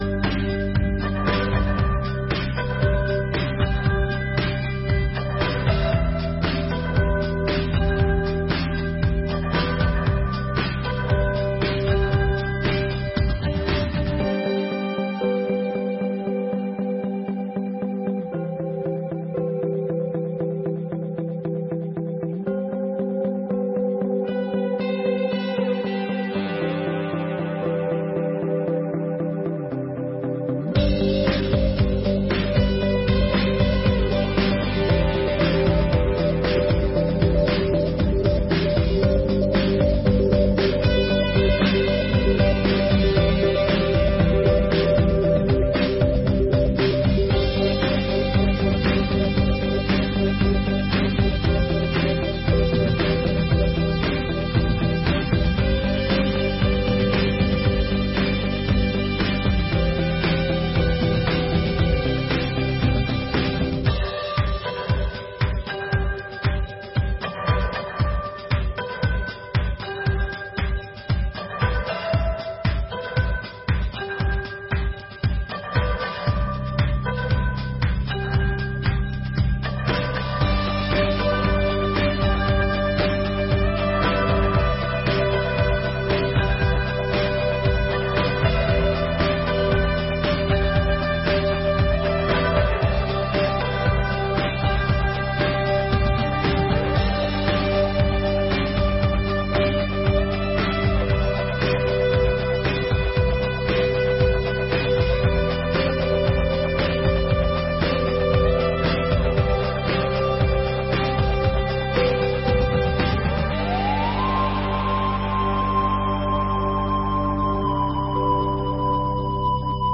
3ª Sessão Ordinária de 2024